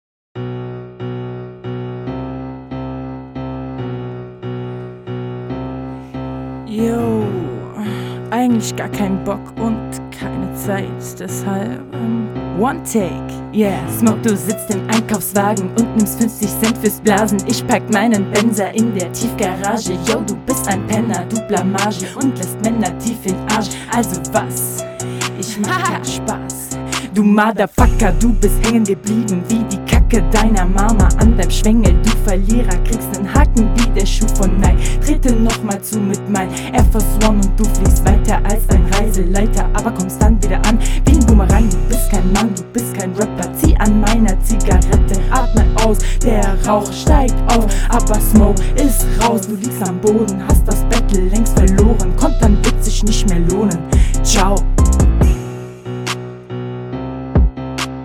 Beat wieder Ohrenkrebs.
Hier etwas besser, man versteht dich.